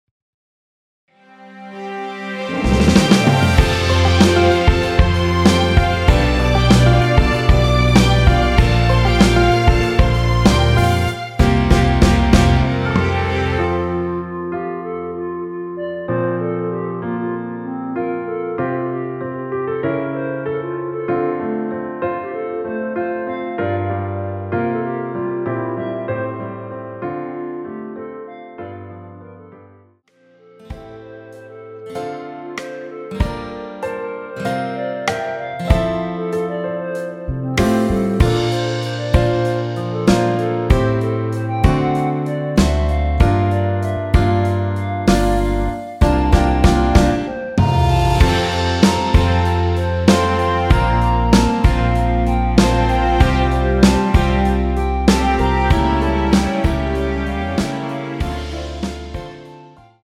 원키에서(+3)올린 (1절앞+후렴)으로 진행되는 멜로디 포함된 MR입니다.(미리듣기및 본문 가사 참조)
앞부분30초, 뒷부분30초씩 편집해서 올려 드리고 있습니다.